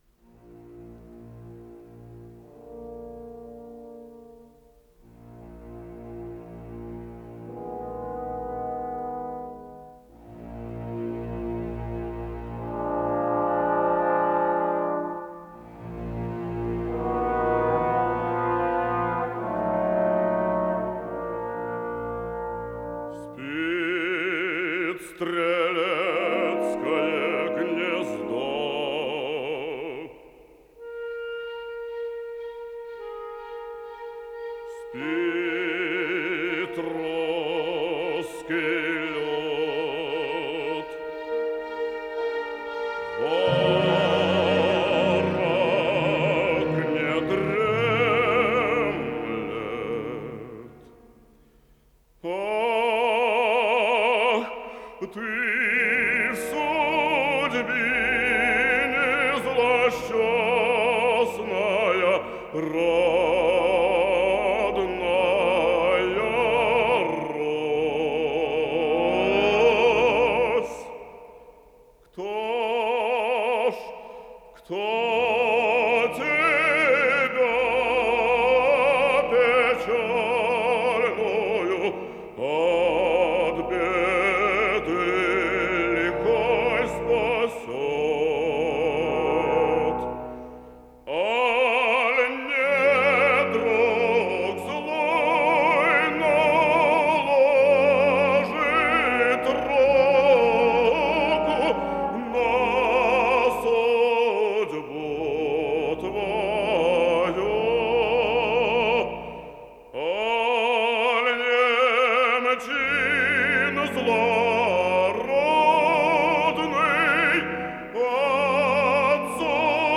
Исполнитель: Солисты, хор и оркестр Государственного академического Большого театра СССР
Название передачи Хованщина Подзаголовок Народная музыкальная драма в 5-ти действиях, 6-ти картинах Код ПКС-025655 Фонд Без фонда (ГДРЗ) Редакция Музыкальная Общее звучание 02:42:21 Дата записи 17.10.1988 Дата добавления 13.10.2024 Прослушать